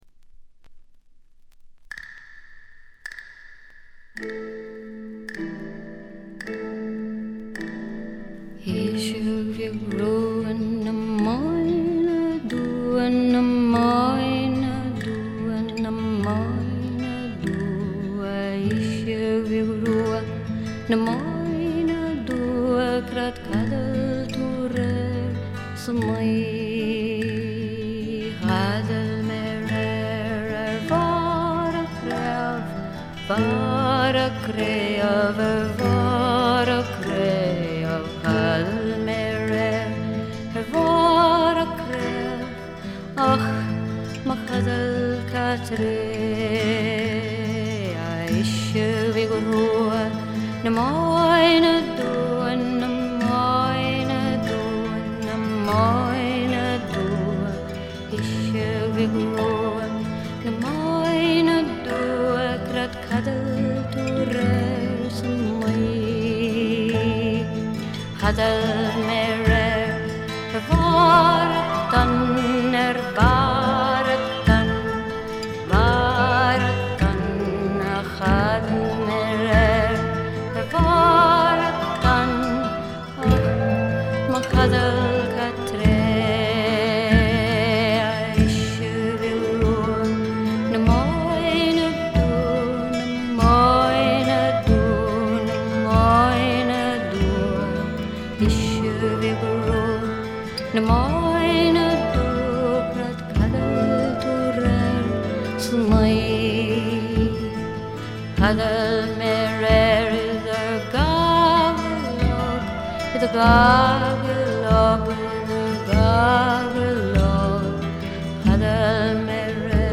メランコリックな曲が多く彼女のヴォーカルは情感を巧みにコントロールする実に素晴らしいもの。
試聴曲は現品からの取り込み音源です。
Harp [Concert Harp]
Vocals, Harp [Irish]
Recorded & mixed At Hollywood Studios, Rome, April 1983.